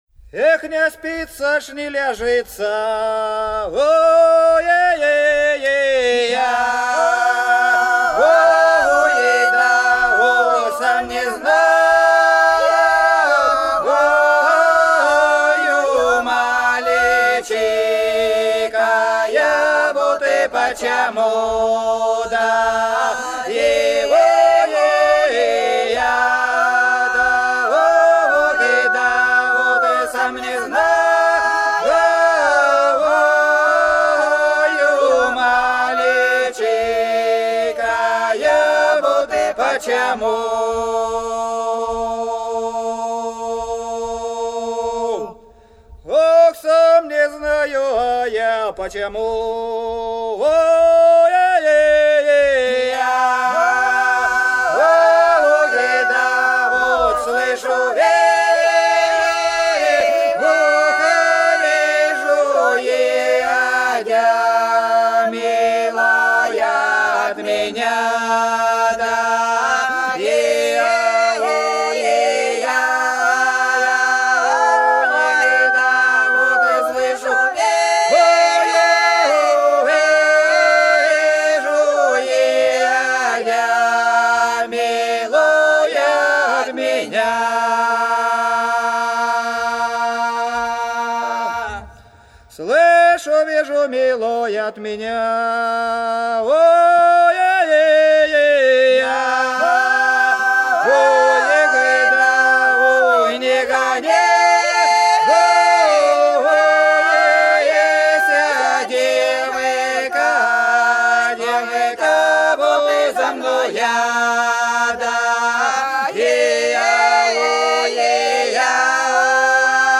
Долина была широкая (Поют народные исполнители села Нижняя Покровка Белгородской области) Эх, не спится, не лежится - протяжная